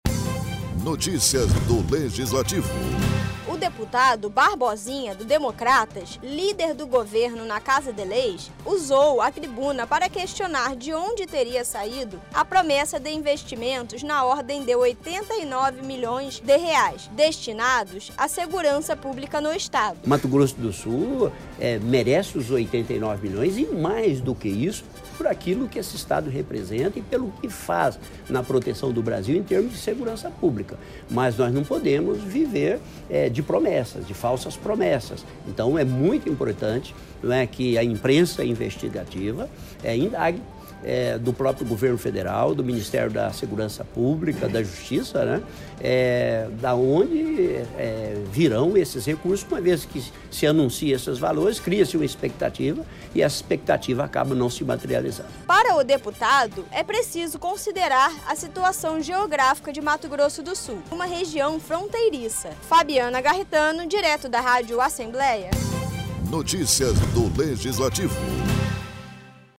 O deputado Barbosinha, do Democratas usou o a tribuna na sessão ordinária da Assembleia Legislativa, para defender melhorias na segurança pública. Cobrando recursos federais, o parlamentar relembrou a promessa que teria sido feita de investimento na marca de R$ 89 milhões.